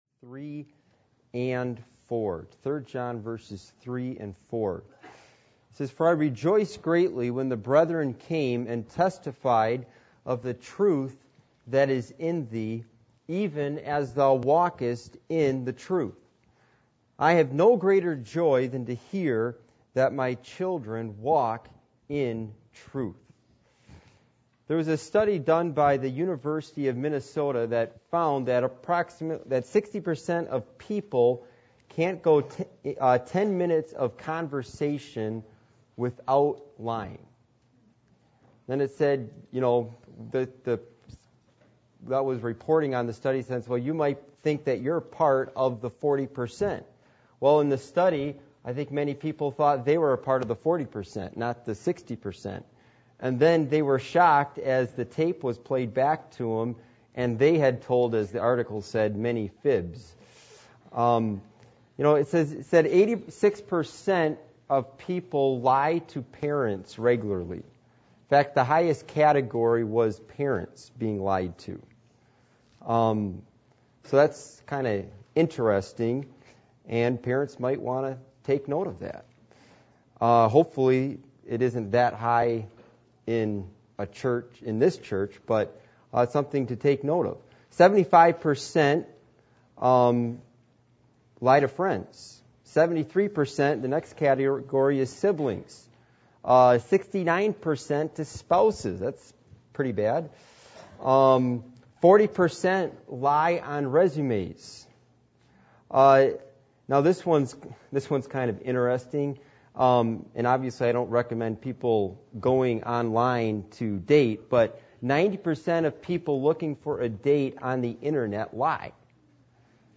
Passage: 3 John 1:2-3 Service Type: Midweek Meeting